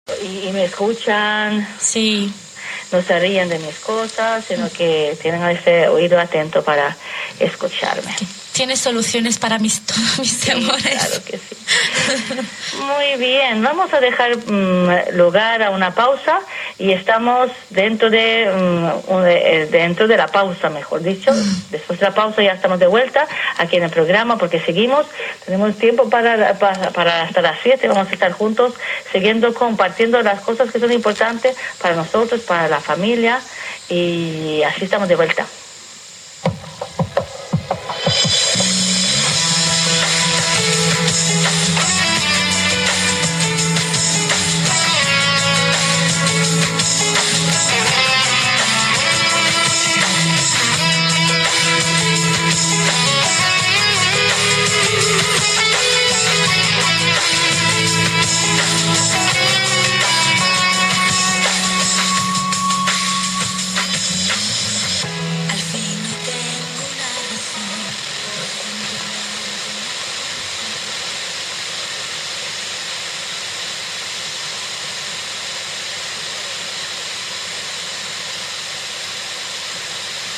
Van de Spaanse zender heb ik een korte opname kunnen maken.
Aan het einde van de opname hoor je de zender wegvallen.
Ik gebruik een losse lint-antenne.